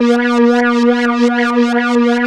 3100 AP  A#4.wav